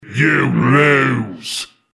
Saxton Hale audio responses